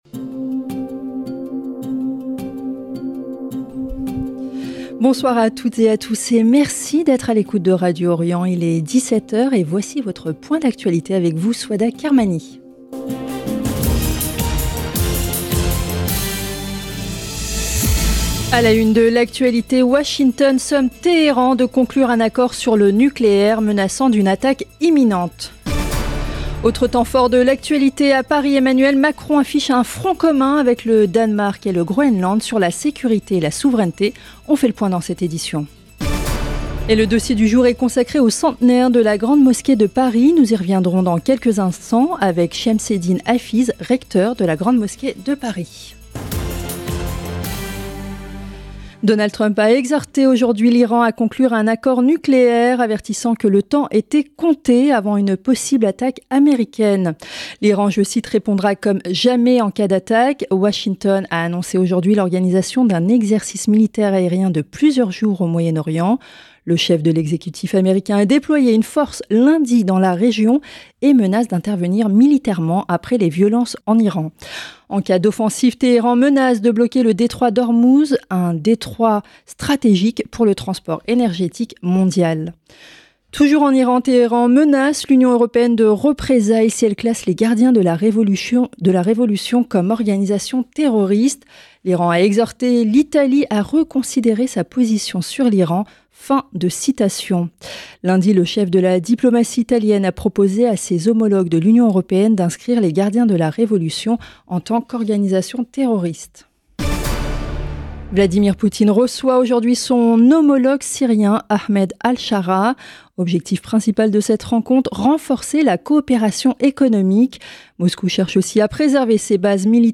France Danemark Groenland Iran Etats-Unis Journal 28 janvier 2026 - 8 min 38 sec Macron en front commun avec le Danemark et le Groenland, pression sur Téhéran Radio Orient Journal de 17H A la une de l’actualité, Washington somme Téhéran de conclure un accord sur le nucléaire, menaçant d’une attaque imminente.